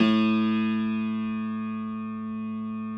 53r-pno05-A0.aif